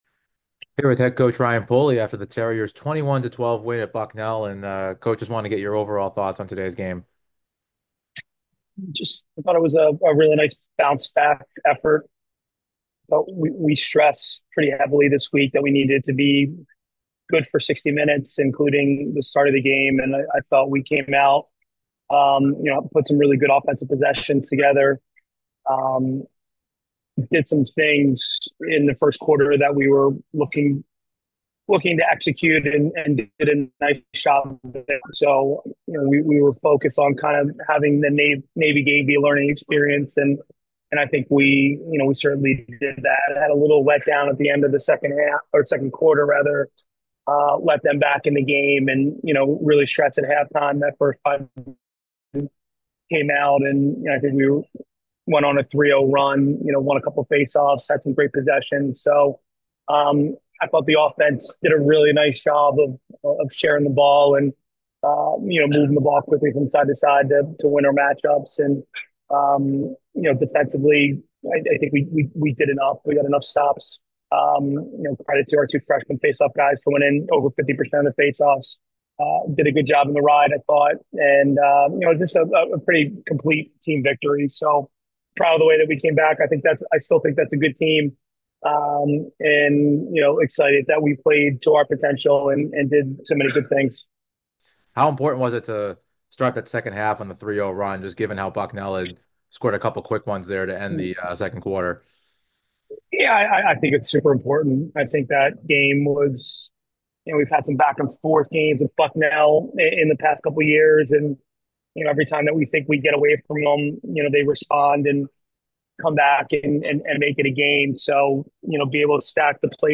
Men's Lacrosse / Bucknell Postgame Interview